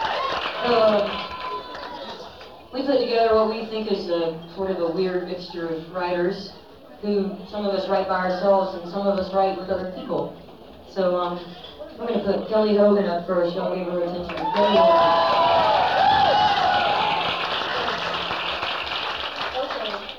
songwriter showcase - eddie's attic - decatur, georgia
02. introduction by amy ray (0:20)